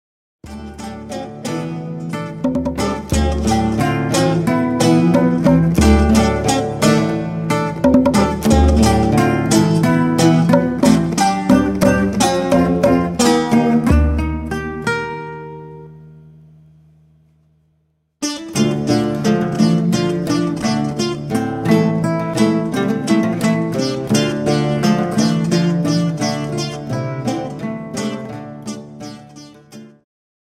ジャンル Progressive
シンフォニック系
ワールドミュージック
民族楽器とシンフォニック・ロックが絶妙に絡み合い異郷に誘う！
oud
acoustic guitar
tombak